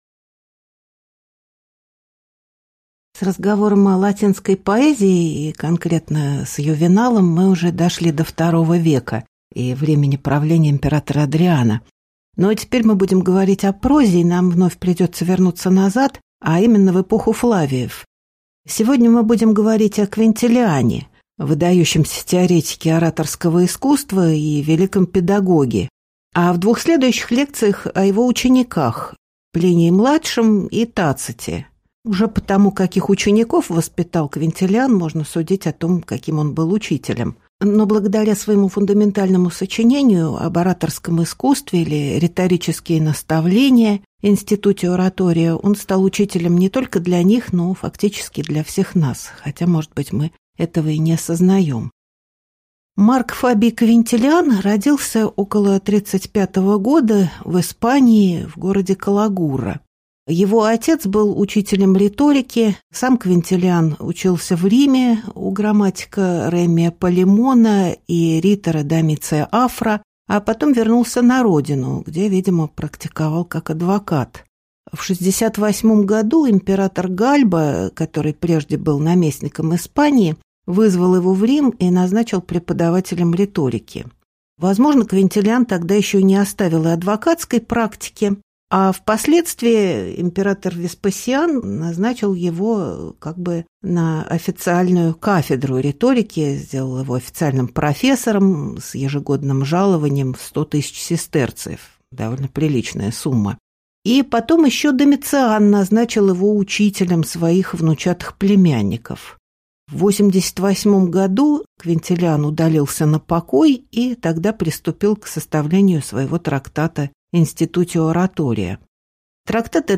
Аудиокнига Лекция «Квинтилиан» | Библиотека аудиокниг